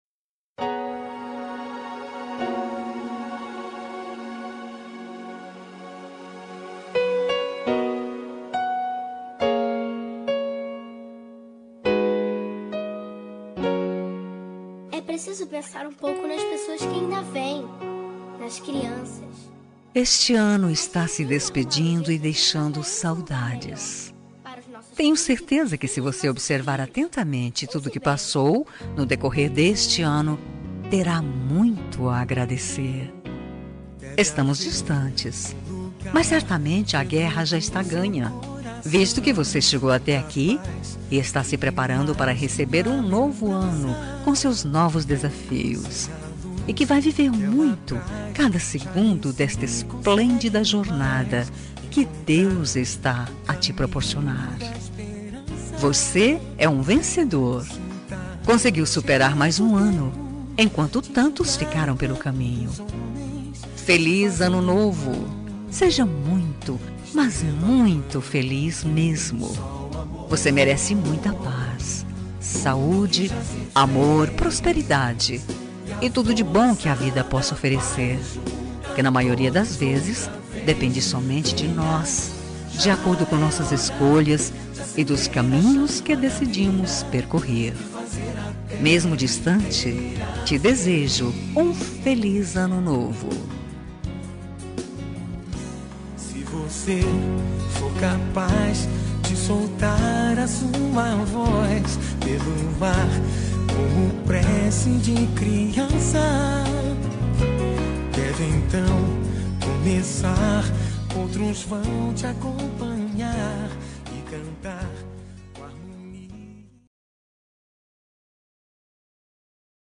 Ano Novo – Distante – Voz Feminina – Cód: 6429